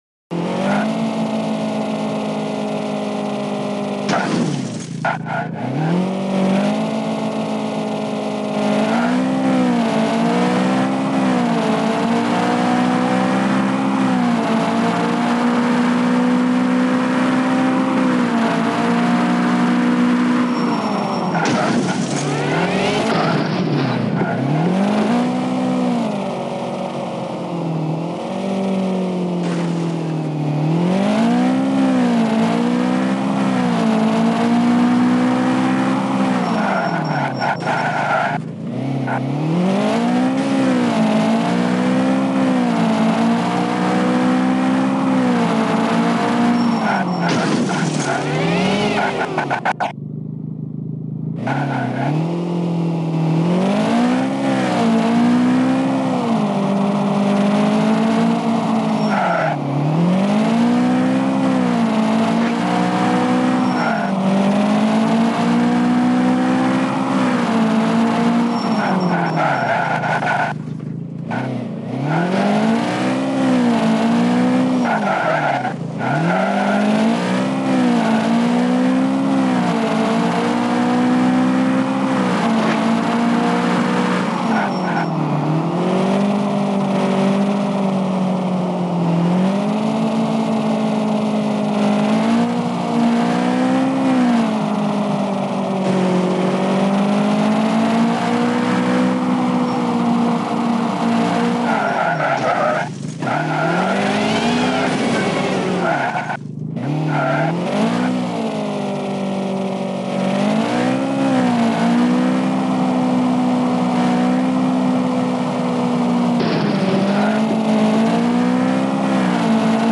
Crazy City Driving Gameplay